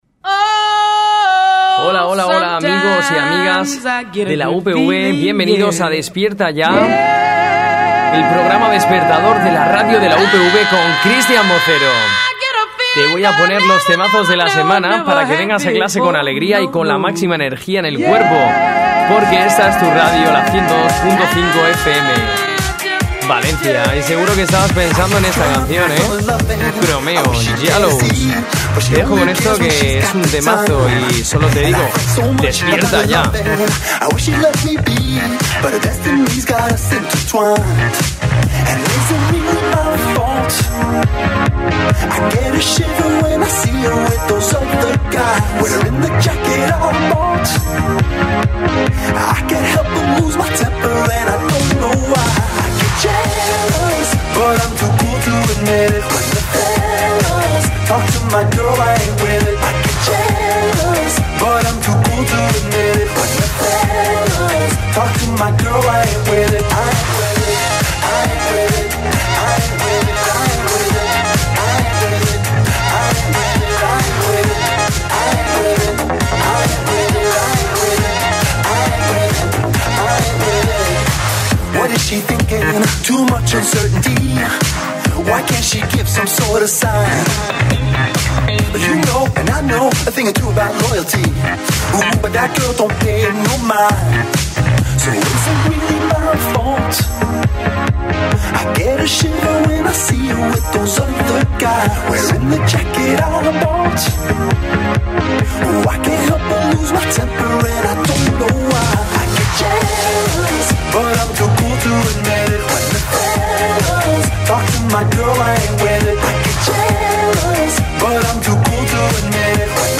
Tipo: Musical Presenta y dirige